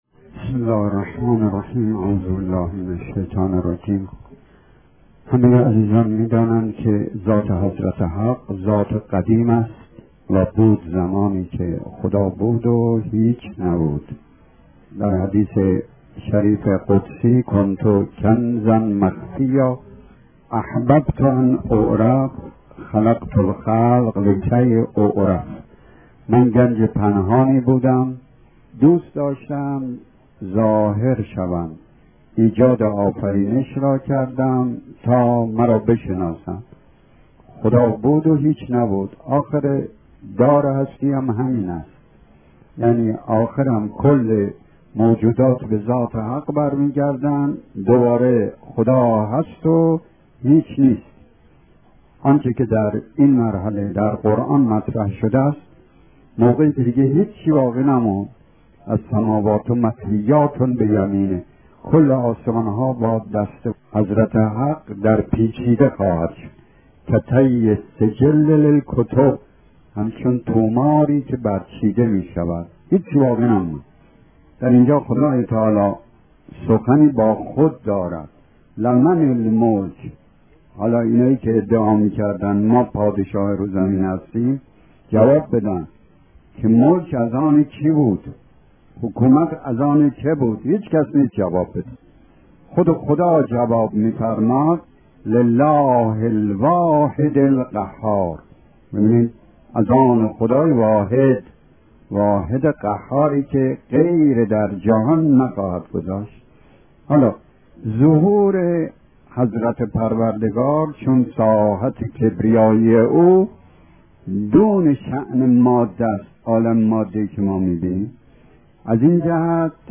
جلسات سخنرانی